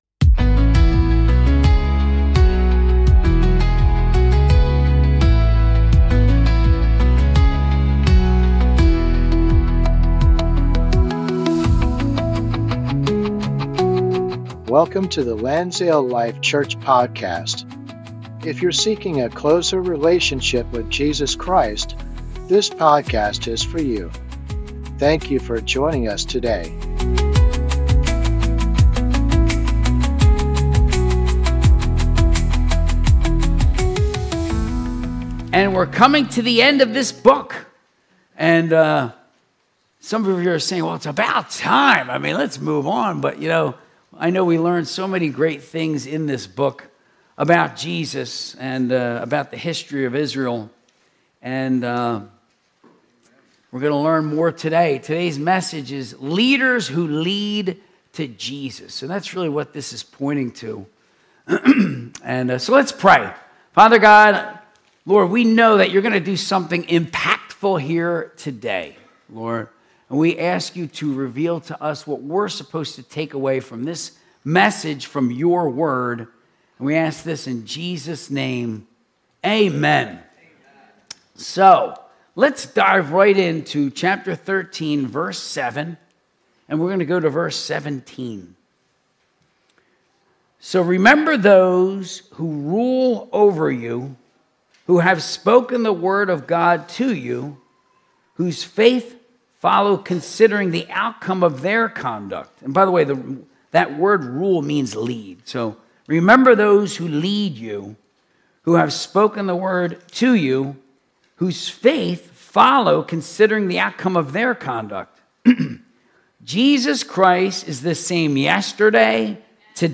Sunday Service - 2026-02-08